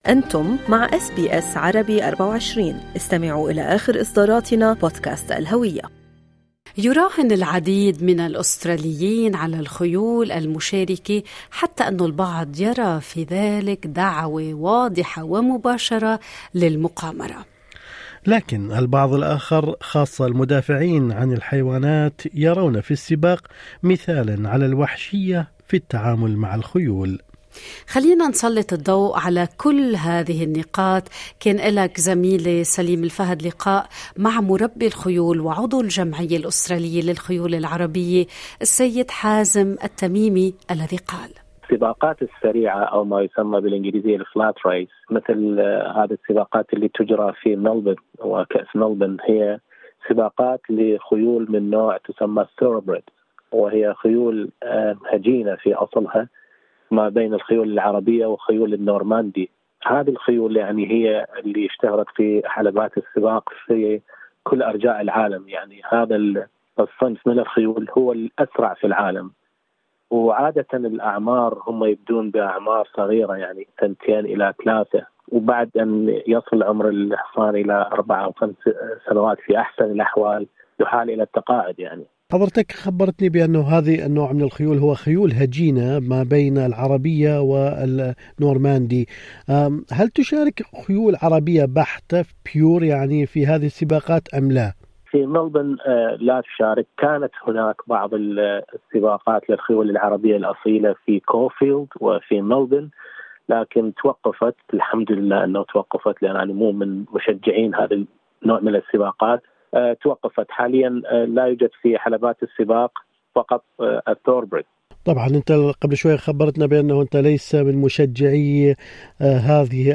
مربي خيول: اصابات الخيول تكون خطيرة في سباقات السرعة مثل كأس ملبورن